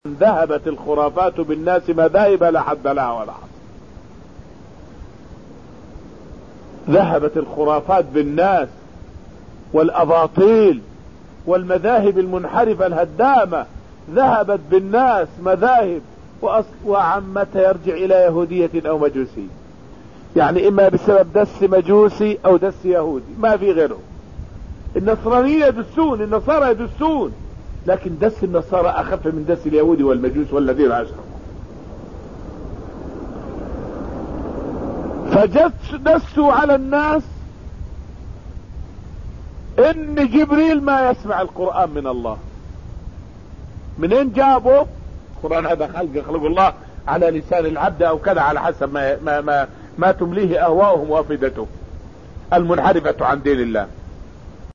فائدة من الدرس الثاني من دروس تفسير سورة الرحمن والتي ألقيت في المسجد النبوي الشريف حول مذاهب وأباطيل الناس في صفة كلام الله تعالى وأسباب نشوئها.